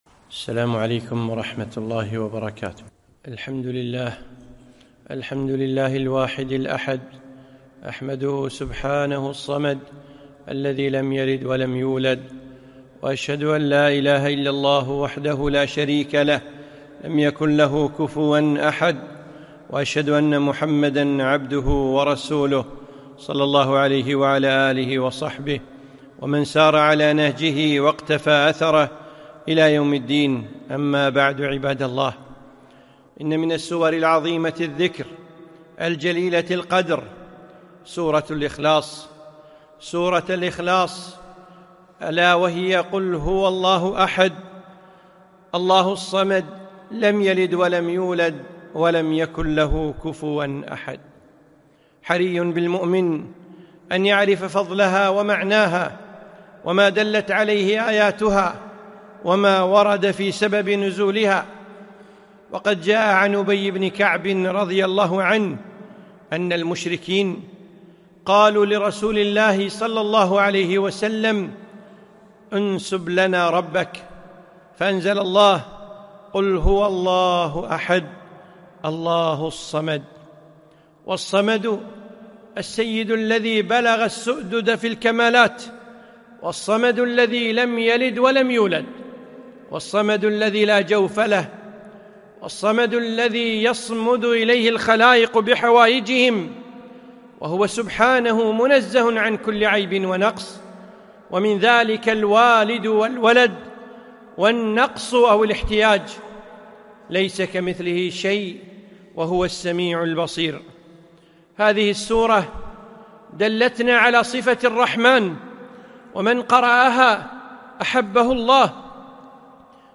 خطبة - ( قل هو الله أحد )